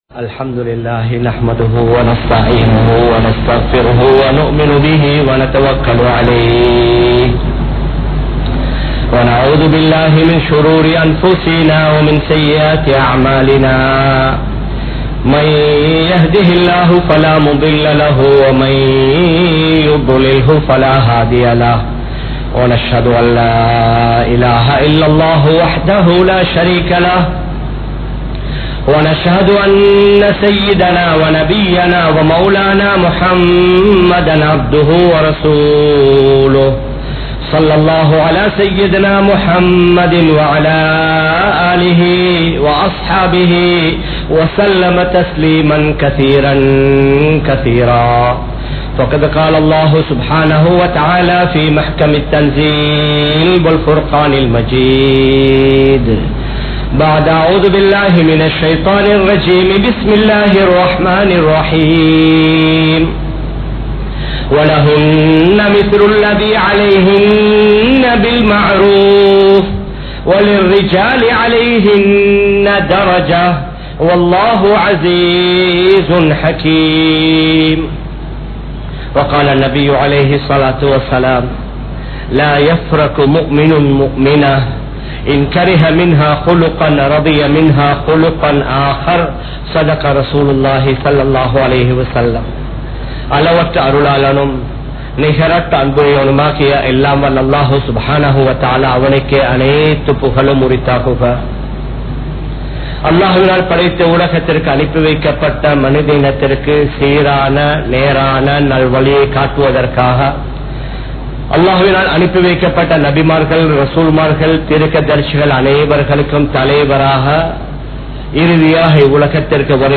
Ungalin Manaiviyai Paaraattungal (உங்களின் மனைவியை பாராட்டுங்கள்) | Audio Bayans | All Ceylon Muslim Youth Community | Addalaichenai
Kollupitty Jumua Masjith